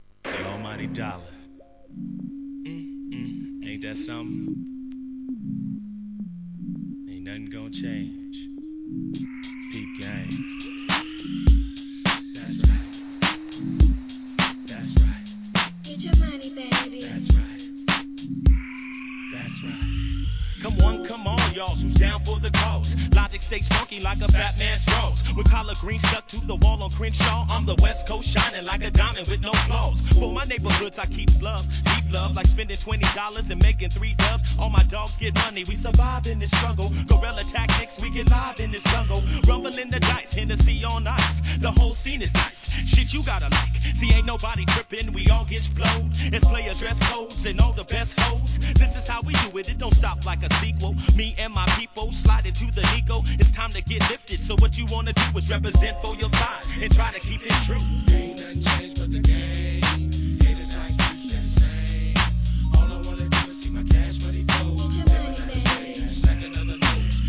G-RAP/WEST COAST/SOUTH
「ハリハリハ〜♪、ハリハリホ〜♪」の分かりやすいフック♪